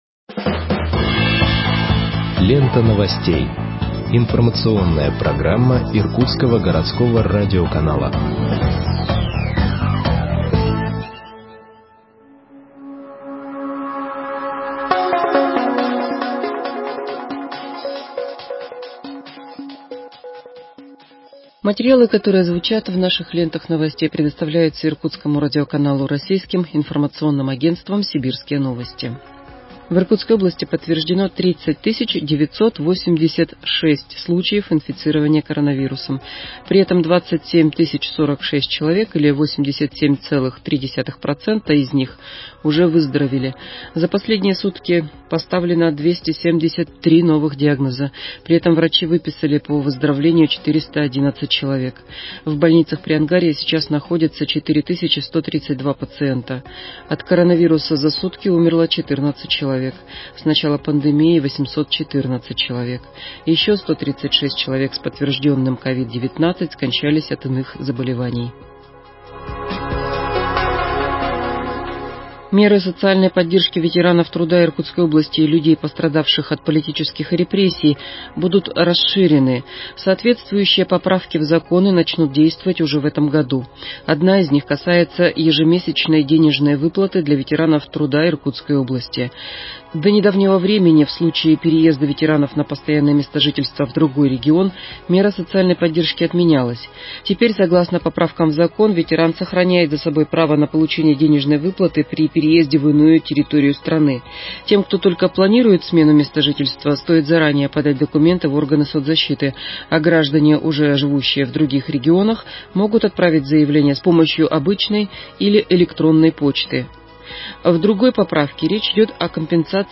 Выпуск новостей в подкастах газеты Иркутск от 26.11.2020 № 2